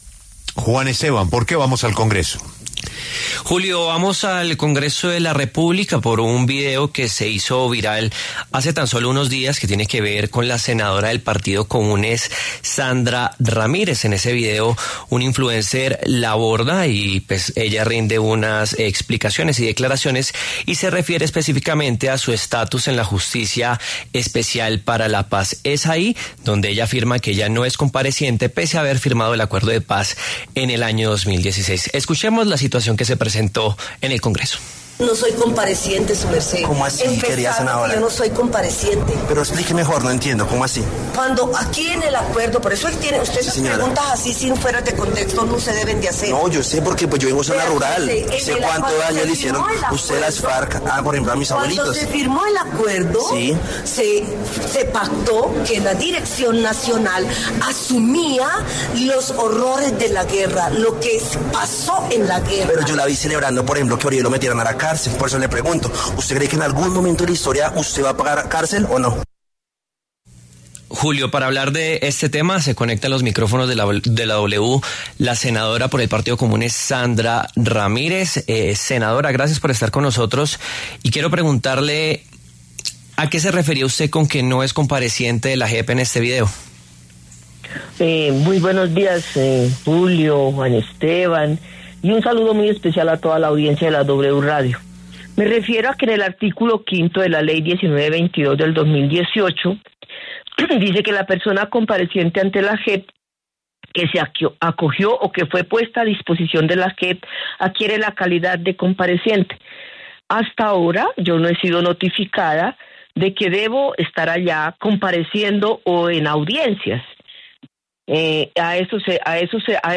La senadora Sandra Ramírez habló en La W a propósito de la polémica por el video en el que negó ser compareciente ante la JEP pese a haber firmado el Acuerdo de Paz.